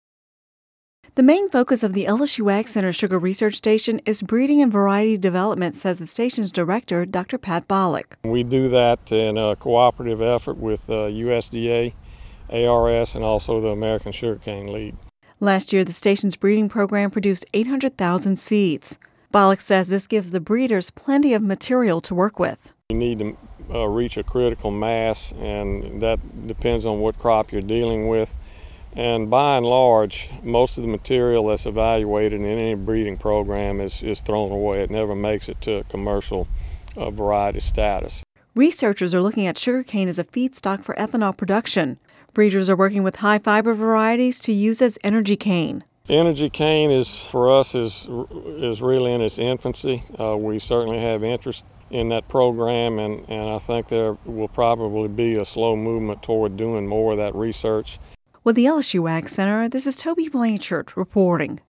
(Radio News 08/02/10)